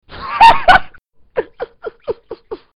laugh2